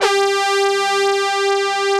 Index of /90_sSampleCDs/Club-50 - Foundations Roland/SYN_xAna Syns 1/SYN_xJX Brass X2